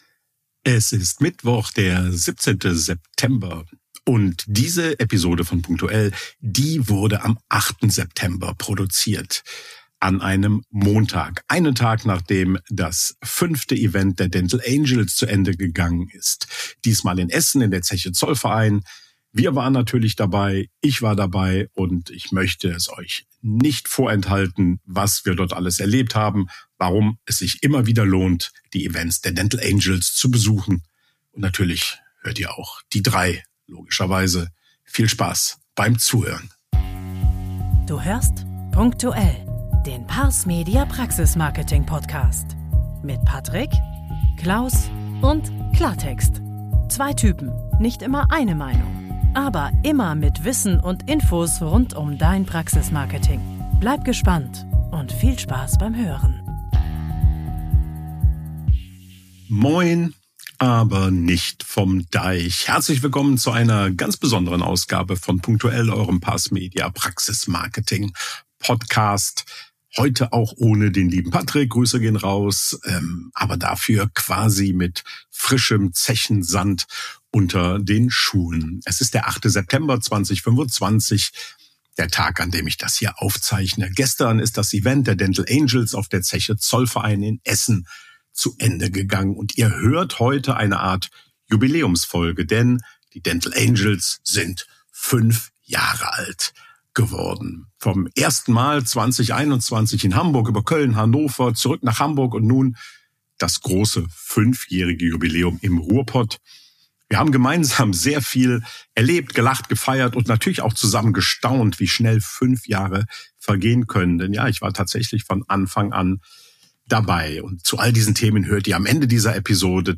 Außerdem geben dir die Angels selbst exklusive Einblicke hinter die Kulissen der Planung und verraten erste Details zum Event 2026 in Hannover.